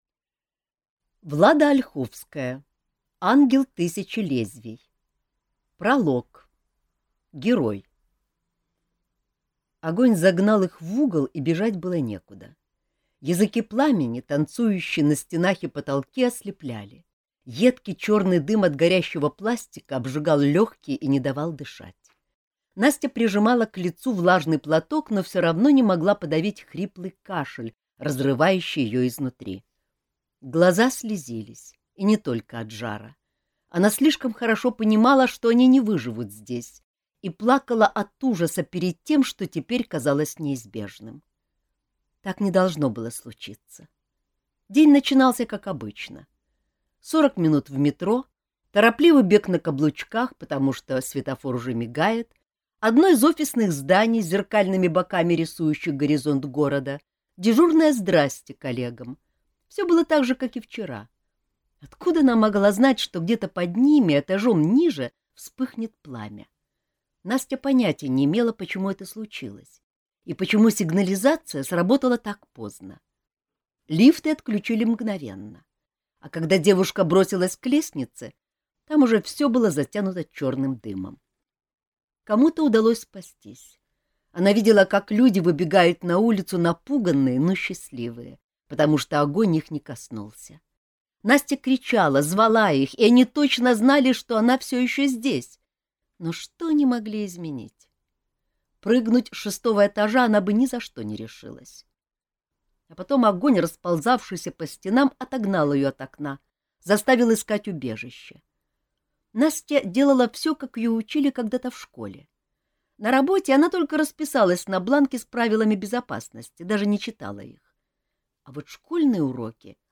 Аудиокнига Ангел тысячи лезвий | Библиотека аудиокниг